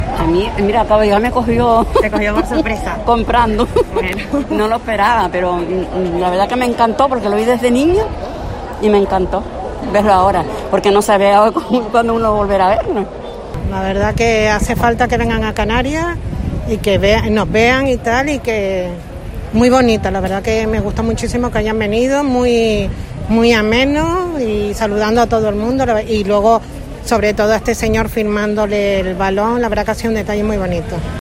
Vecinas de la capital nos cuentan qué les ha parecido esta visita real